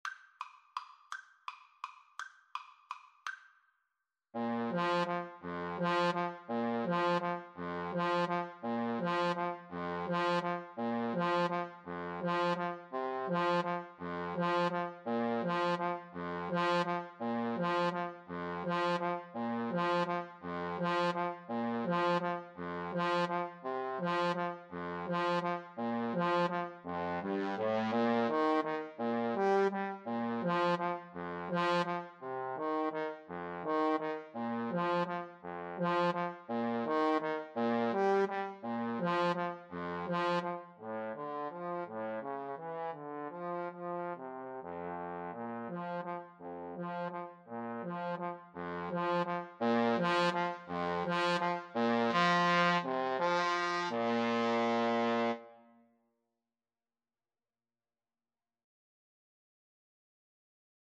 3/4 (View more 3/4 Music)
Tempo di valse =168
Classical (View more Classical Trombone Duet Music)